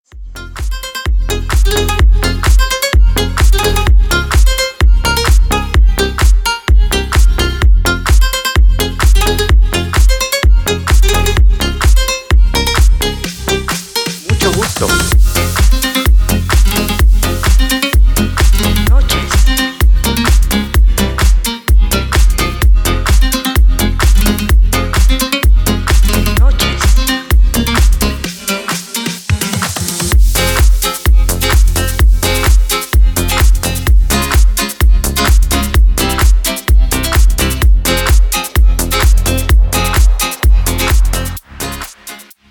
• Качество: 320, Stereo
гитара
мужской голос
ритмичные
deep house
забавные
женский голос
Electronic
басы
качающие
энергичные
Забавный дип-хаус